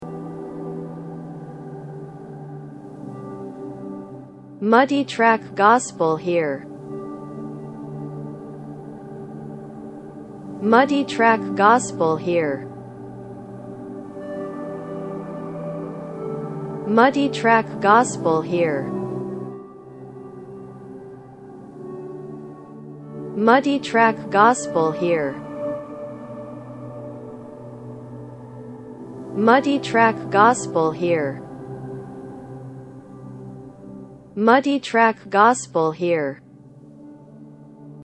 Keys Pad_1